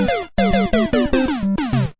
Lose.mp3